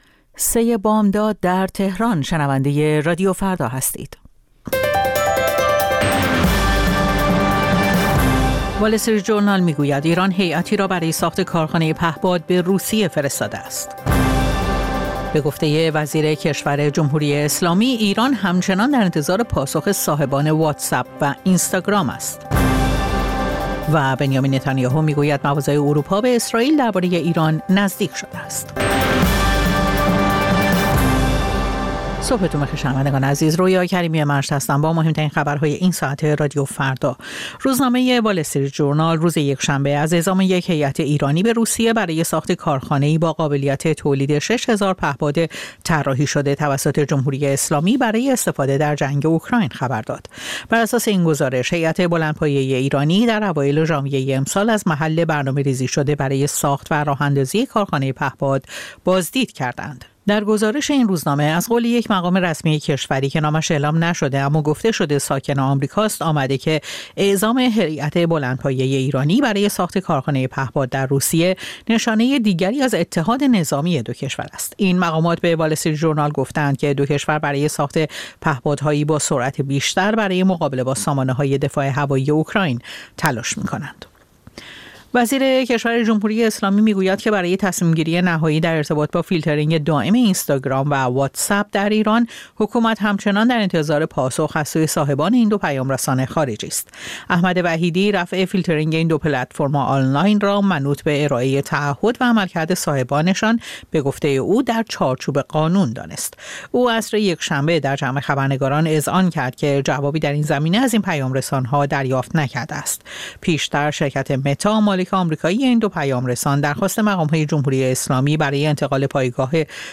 سرخط خبرها ۳:۰۰